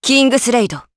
Hilda-Vox_Kingsraid_jp.wav